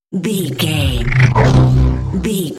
Sci fi alien energy pass by 817
Sound Effects
futuristic
high tech
pass by
sci fi